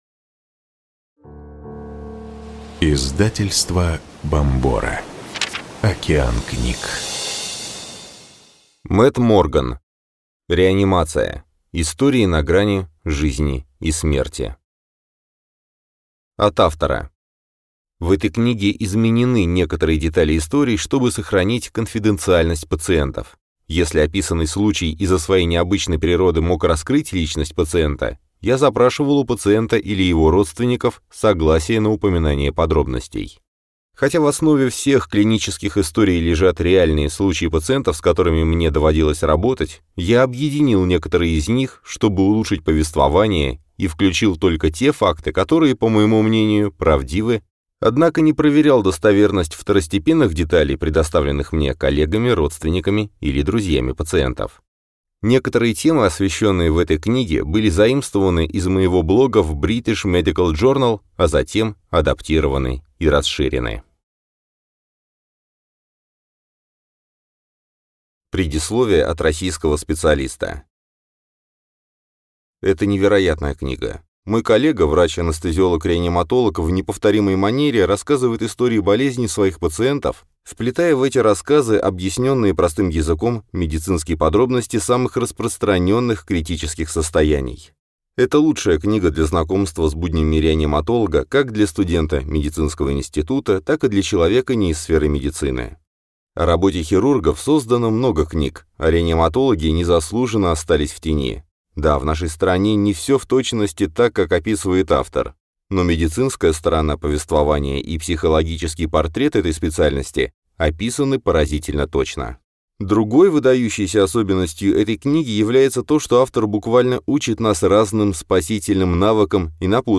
Аудиокнига Реанимация. Истории на грани жизни и смерти | Библиотека аудиокниг